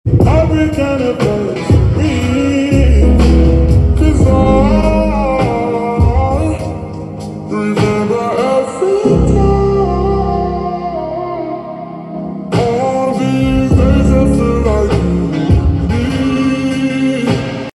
slowed n reverb